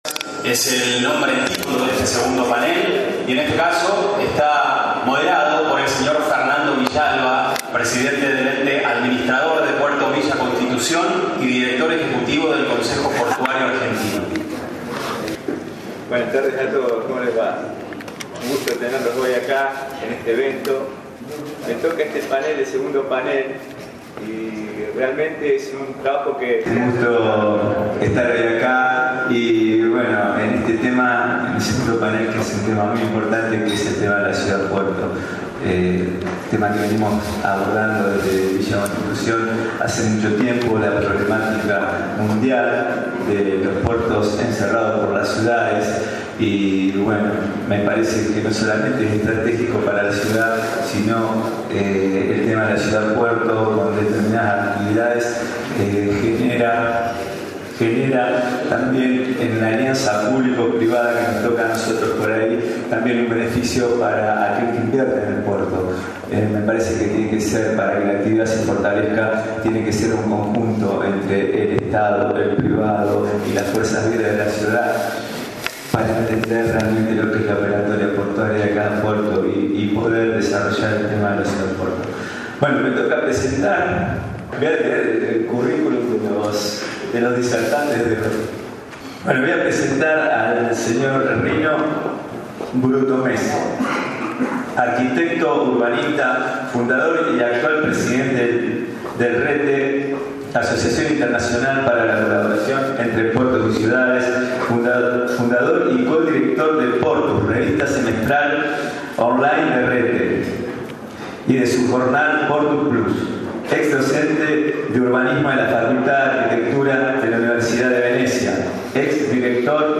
Desde el Consejo Portuario Argentino se realizó  el “II Encuentro Multisectorial”.
Moderador: Fernando Villalba, Presidente del Ente Administrador Puerto Villa Constitución.